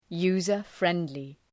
Shkrimi fonetik{,ju:zər’frendlı}